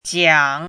chinese-voice - 汉字语音库
jiang3.mp3